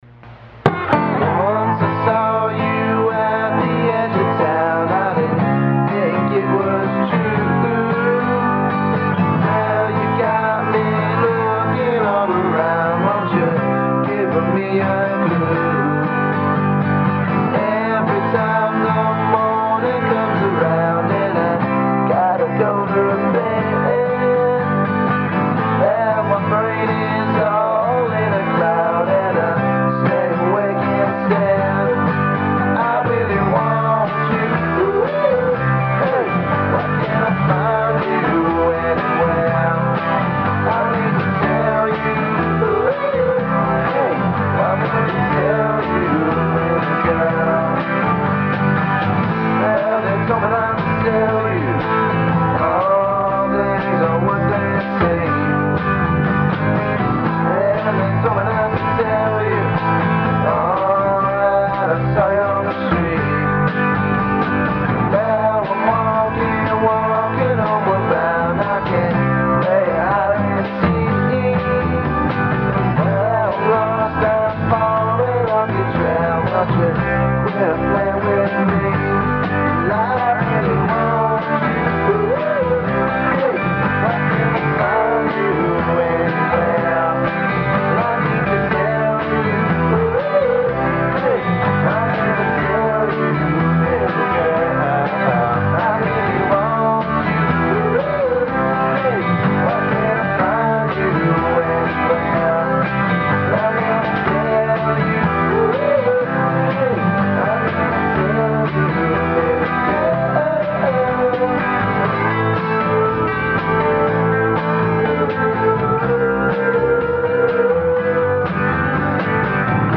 fuzzed out, lo-fi on steriods pop